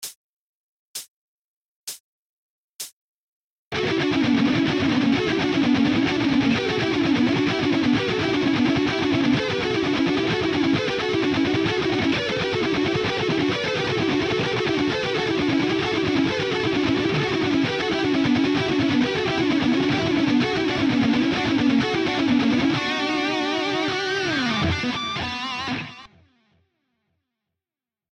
угу-угу, играешь ты классно, а со сведением проблемушка.